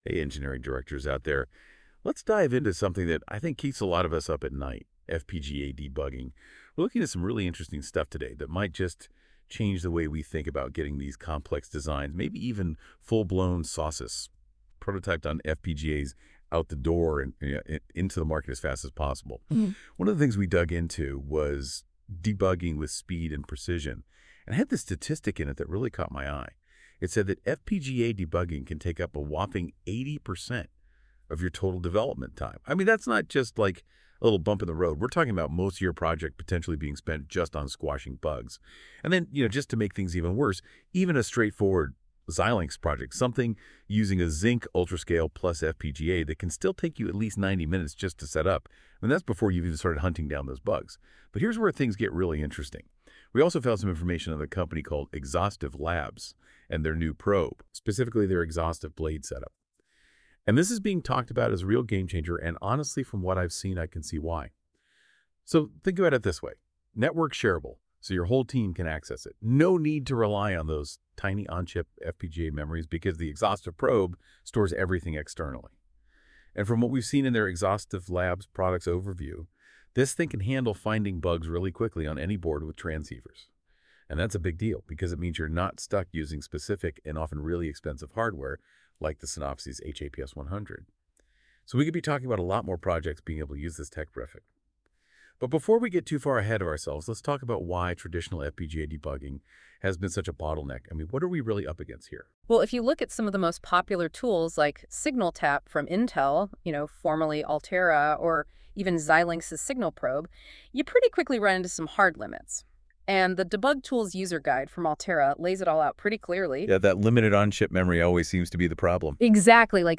If you’d like to understand what Exostiv Labs focuses on, why we do this and the value we bring to the FPGA and SoC debug, verification and validation listen to the following ‘fireside chat’ style discussion.
(The podcast was nicely generated with the help of NotebookLM).